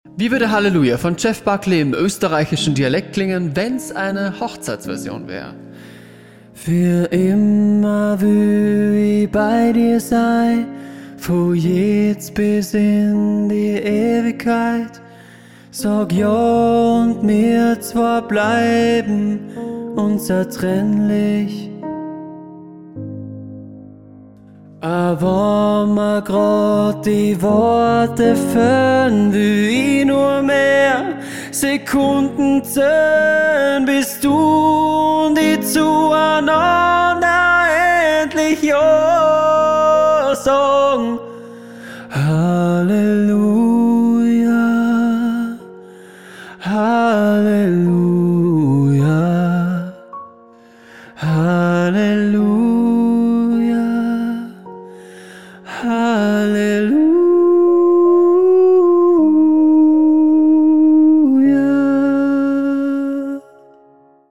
Austro-Pop